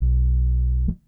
bass4.wav